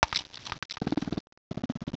sovereignx/sound/direct_sound_samples/cries/bombirdier.aif at master